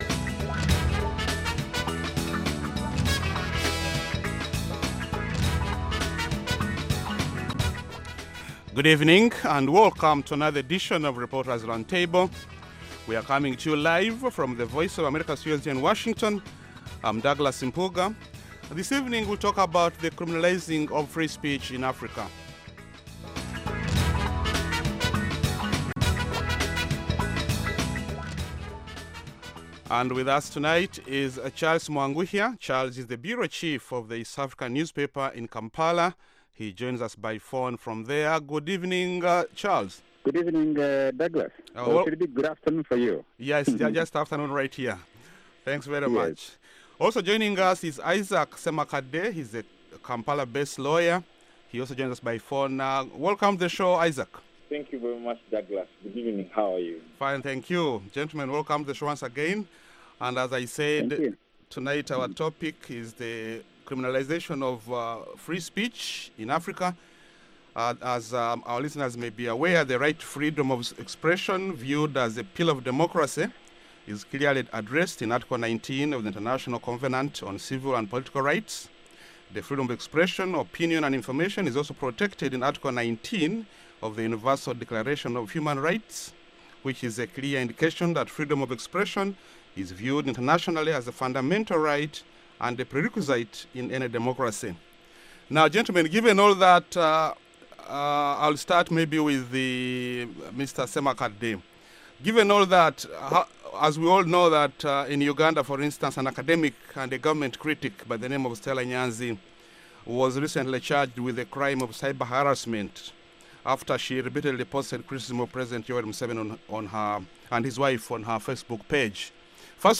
along with a lively panel of journalists, who analyze the week’s major developments in Africa.